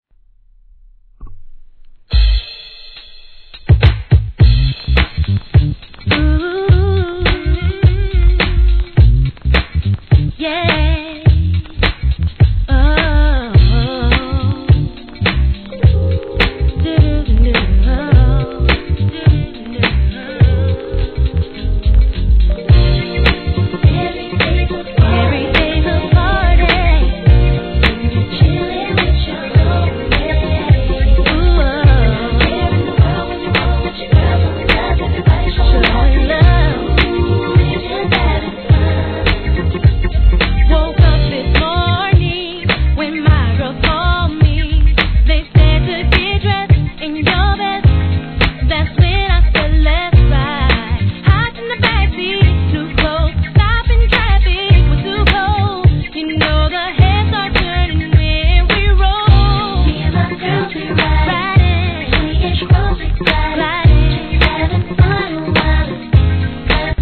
HIP HOP/R&B
流れる様な気持ちよいリズムにピアノのメロディーが入る極上スムース・ダンサー♪